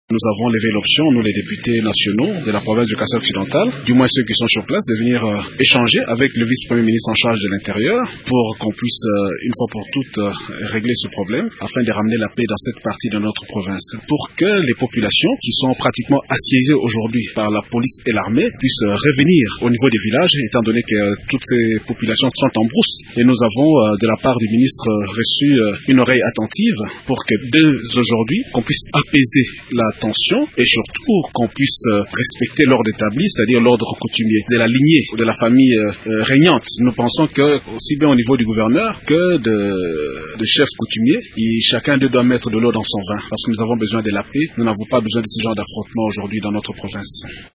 Le député Clément Kanku explique, à Radio Okapi, le but de leur démarche auprès du ministre de l’Intérieur: